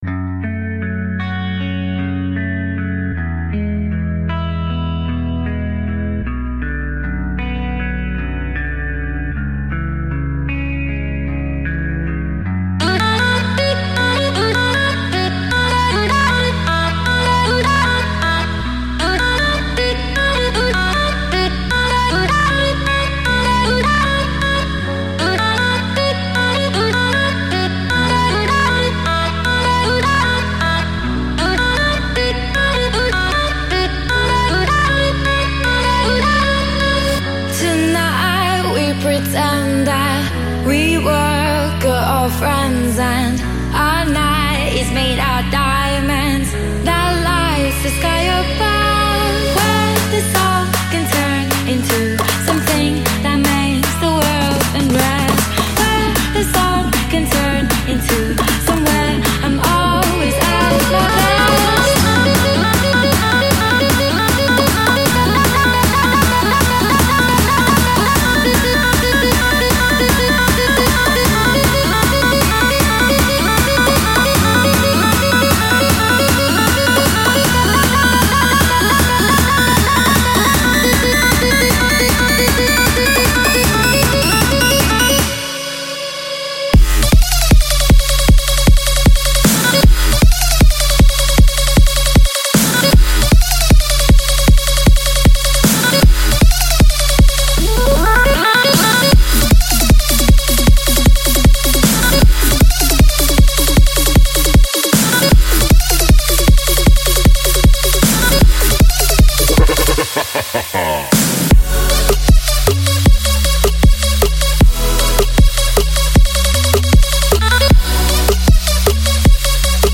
Latest Pop Songs